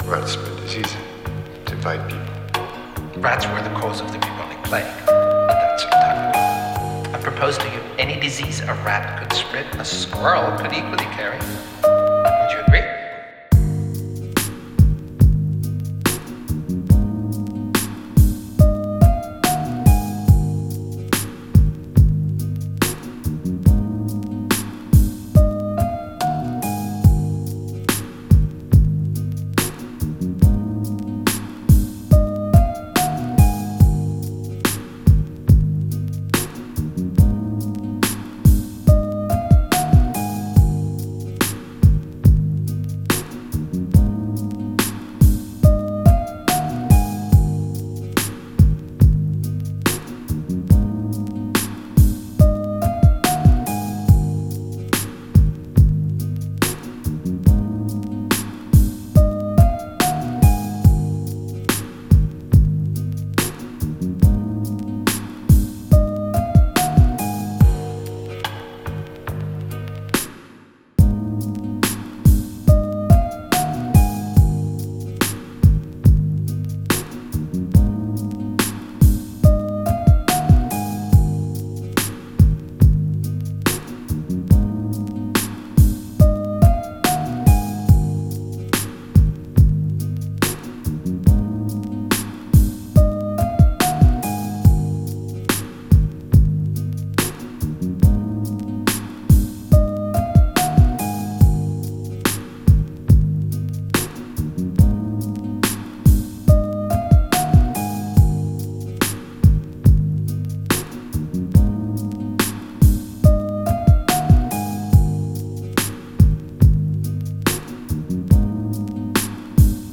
GRATUITATrapPiano
BPM130
GÉNEROTrap